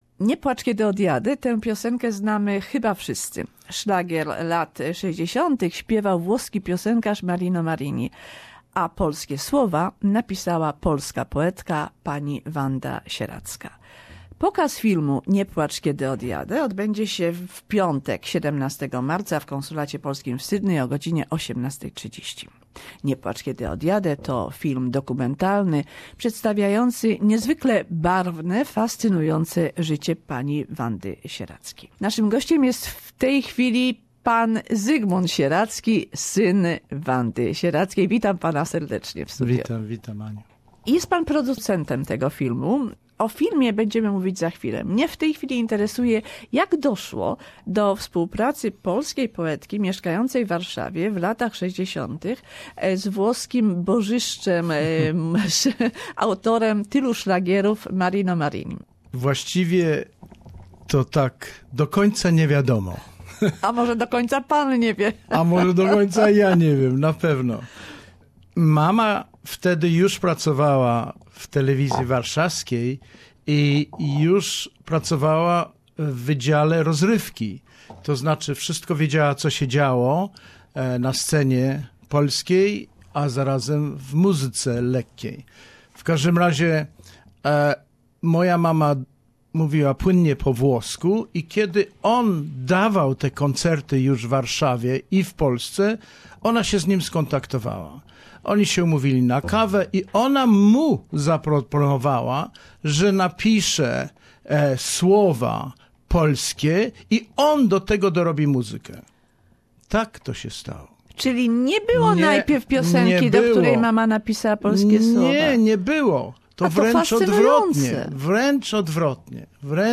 Nie placz kiedy odjade - the lyrics to that popular Sixties song were by Polish poet Wanda Sieradzka. More in the interview